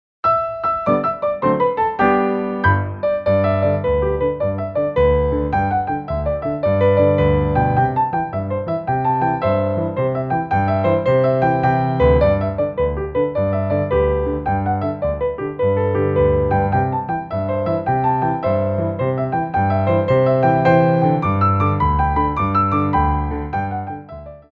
Piano Arrangements of Popular Music
6/8 (16x8)